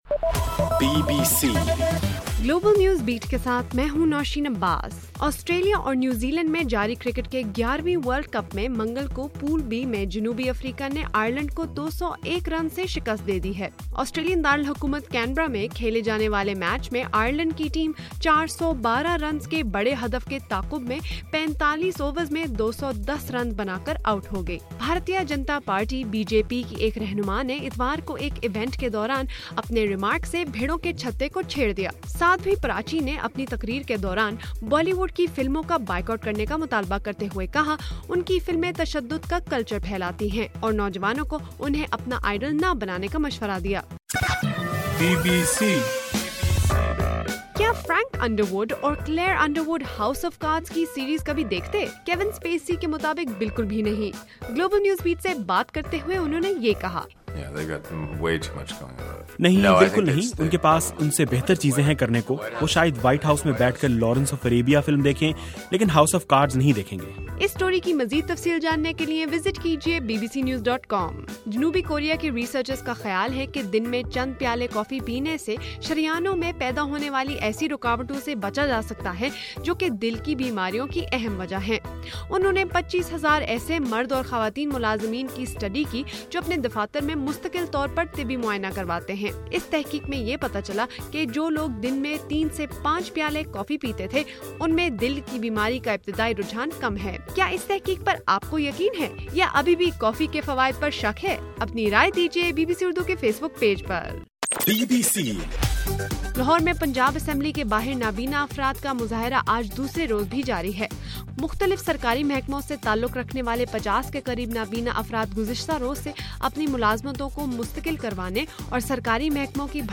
مارچ 4: صبح 1 بجے کا گلوبل نیوز بیٹ بُلیٹن